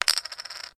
chip2.mp3